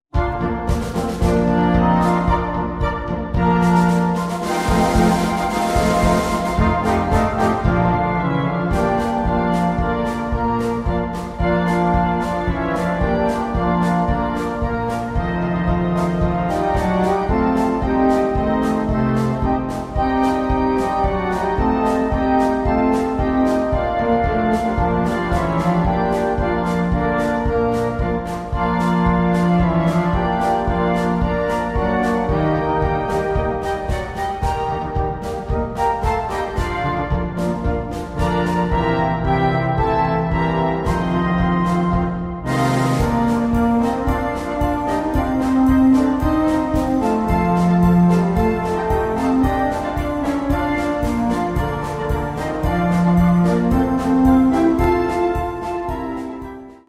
Karneval
Besetzung Blasorchester